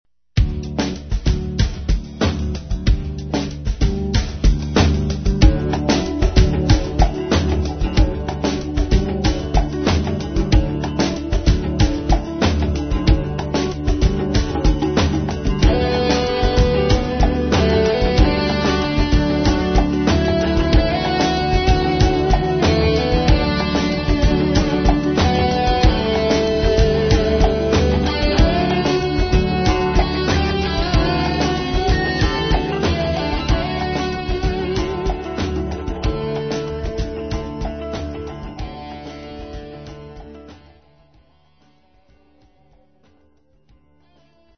Guitar
Vocals / Drums
Bass Guitar
Keyboards
Percussion